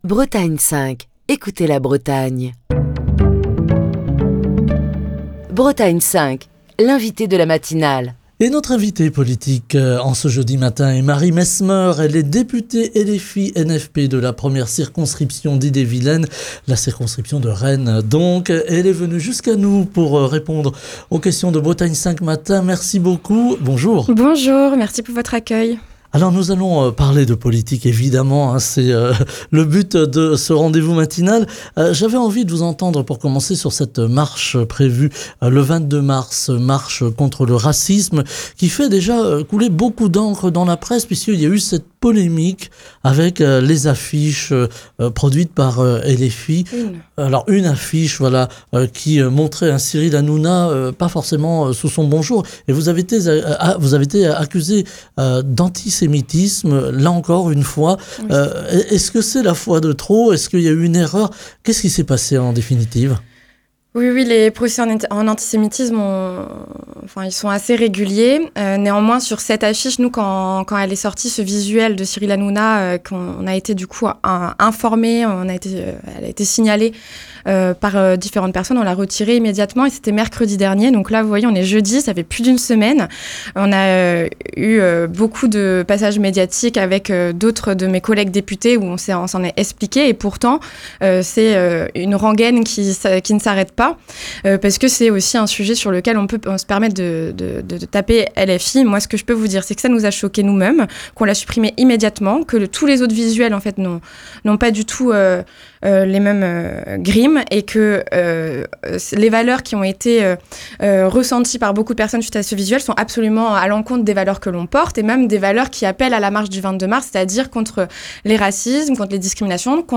Marie Mesmeur, députée LFI-NFP de la 1ᵉᵗʰ circonscription d’Ille-et-Vilaine, était l'invitée de la matinale de Bretagne 5 ce jeudi.